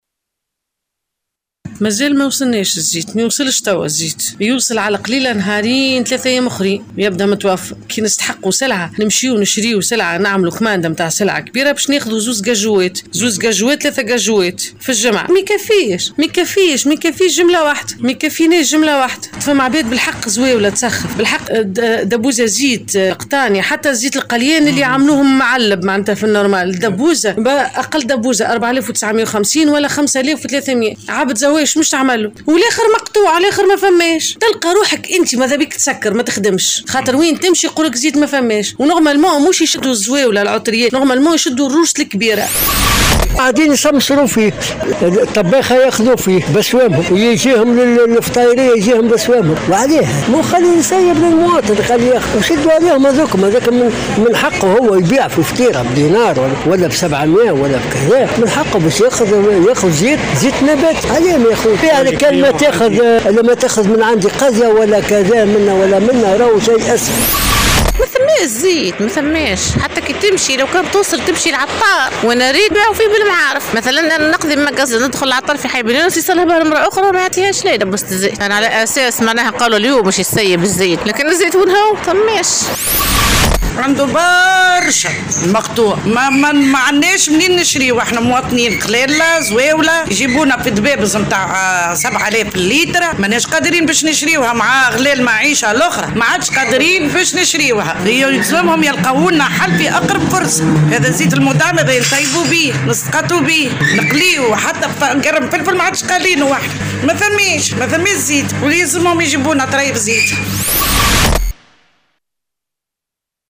عبّر عدد من المواطنين عن امتعاضهم من تواصل أزمة الزيت النباتي المدعّم، والنقص الحاد المسجل في الأسواق.
وقالوا في تصريحات لـ"الجوهرة أف أم" إن "زيت الحاكم" ملاذ "الزوّالي" ، وإنهم غير قادرين على اقتناء الزيوت الأخرى المعلّبة نظرا لارتفاع أسعارها. وأوضحوا أن الحصول على قارورة "زيت مدعّم" أصبح أمرا مستحيلا ويتطلّب الكثير من الجهد والمعاناة.